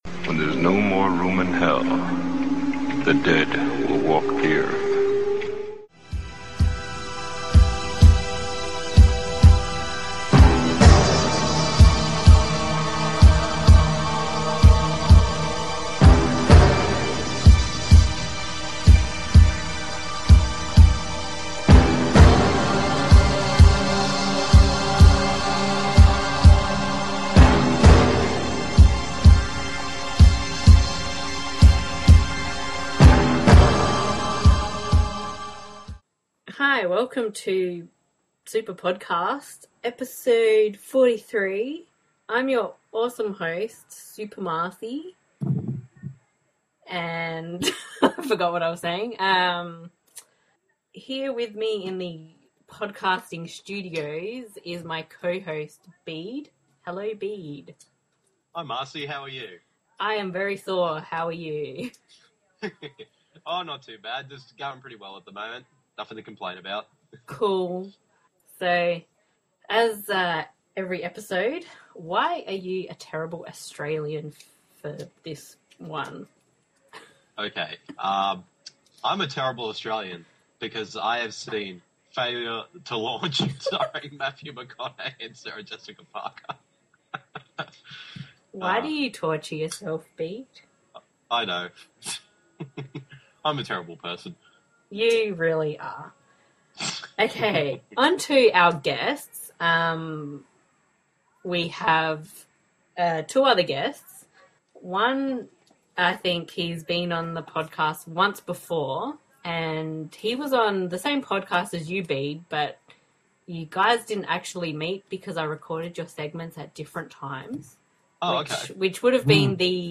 Thanks to our guests for joining us, it was a real pleasure and a great discussion.